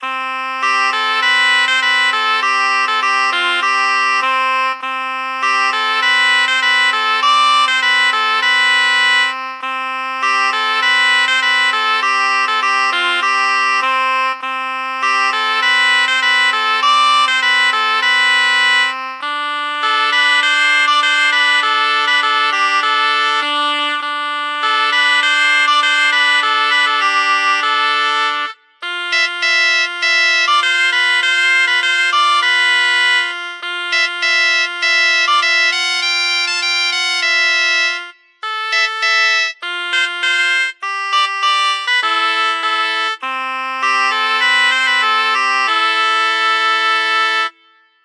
ゆるーい民族音楽風BGM。
無限ループほのぼの民族音楽